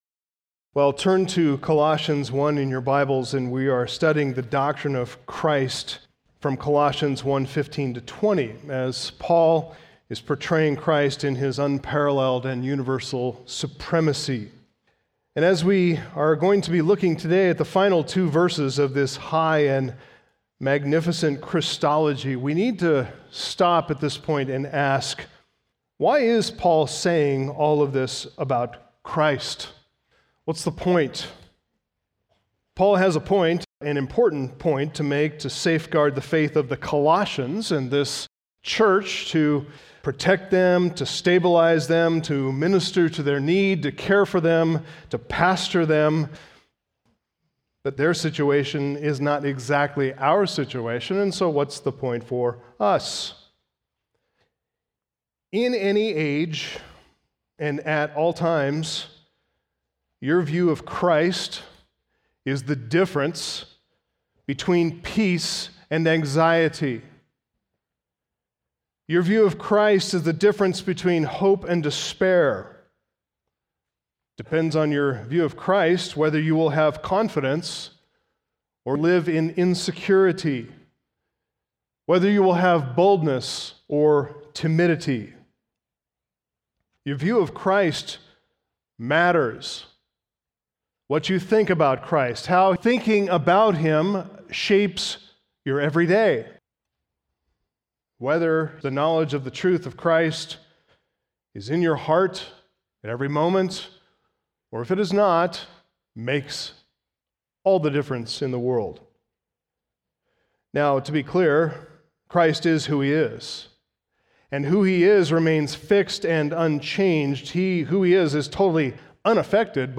Sermons , Sunday Morning